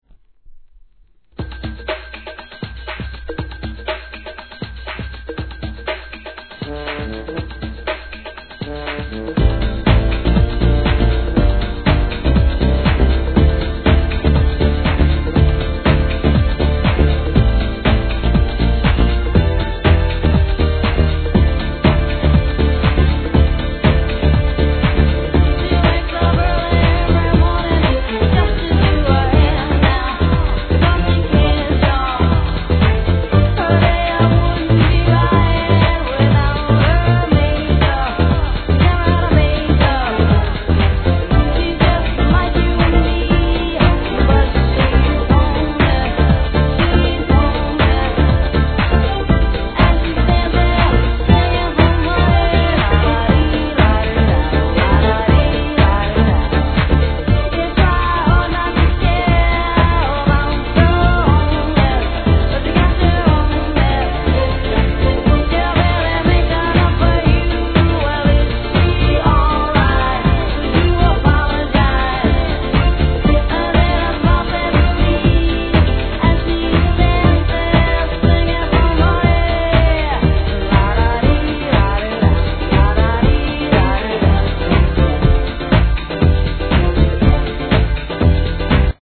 HIP HOP/R&B
一度聴いたら忘れられない「ララリ〜ラルラ」♪このかったるさが癖になる